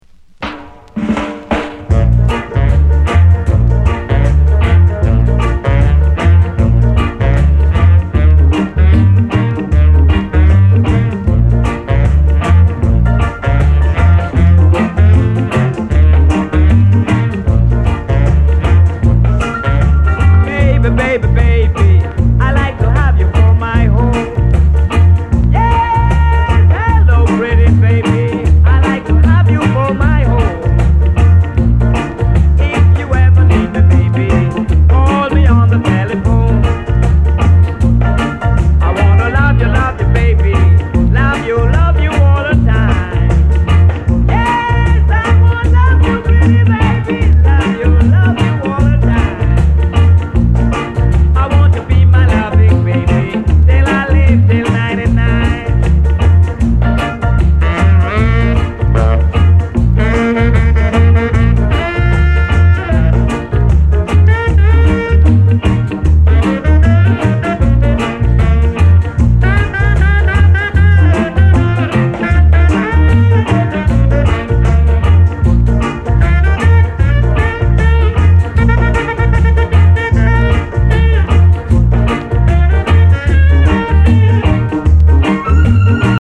銃声やハモンド効かせたスキンズ・チューンなどルーディー・チューン満載！